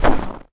STEPS Snow, Run 09.wav